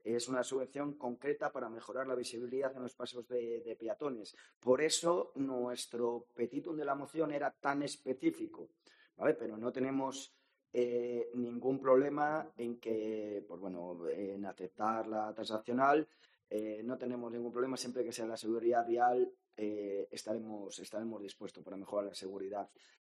José Manuel Lorenzo Serapio, portavoz Vox. Moción pasos de peatones